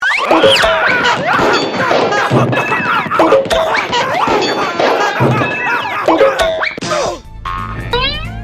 Звуки падения по лестнице